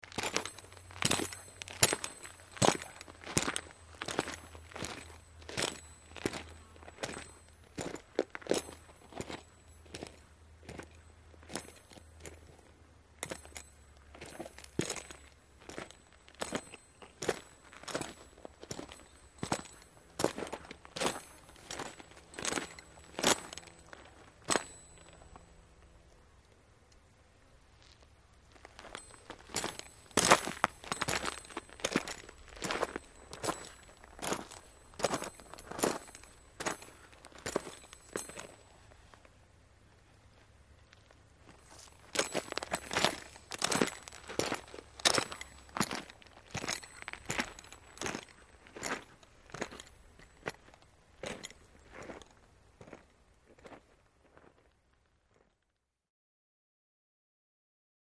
SpursPaceSlowOnDir BB013701
Spurs Pace Slow On Dirt; Slow Impact Of Boots On Dirt With Metal / Spur Jingle. Heavier Steps With Low Insect Buzz By. Walk, Stop Turn, Walk.